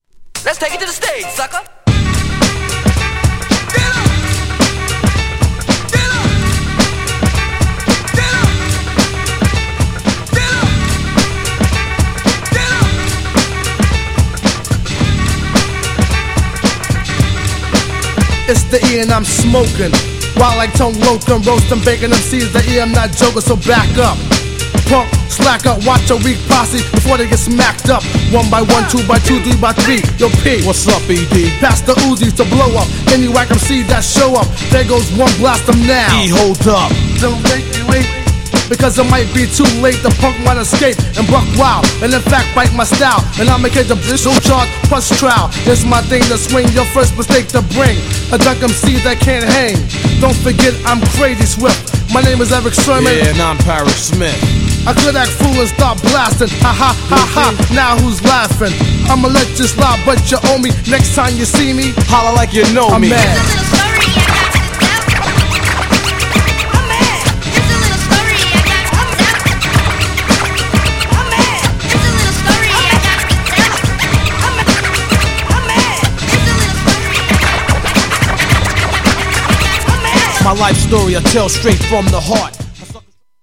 GENRE Hip Hop
BPM 111〜115BPM
FUNKYなネタ使い
クロいHIPHOP # ハーコーチューン